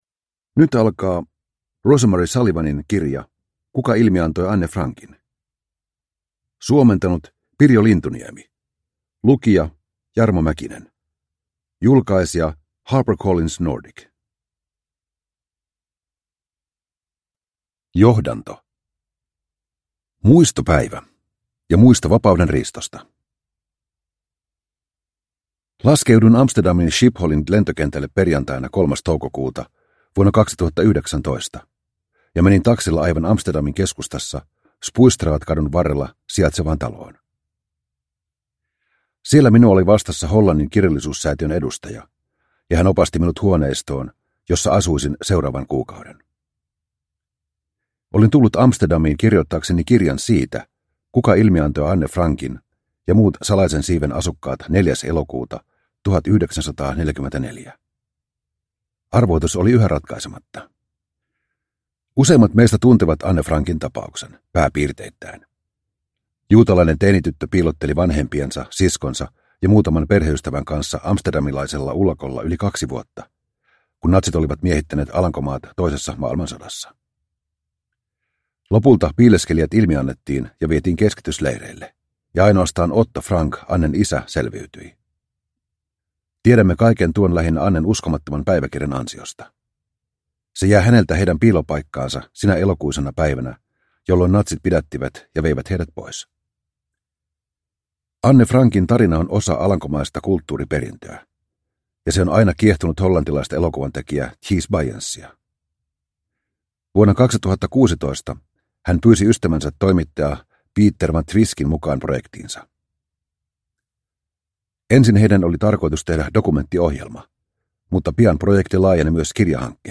Kuka ilmiantoi Anne Frankin? – Ljudbok – Laddas ner